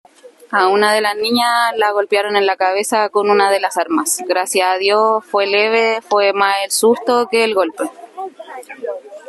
Una vecina que presenció los hechos y que posteriormente auxilió a la familia afectada, aseguró que actuaron con extrema violencia.